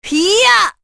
Epis-Vox_Attack3.wav